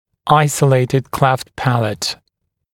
[‘aɪsəleɪtɪd kleft ‘pælət][‘айсэлэйтид клэфт ‘пэлэт]изолированная расщелина неба